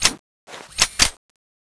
change_to_b.wav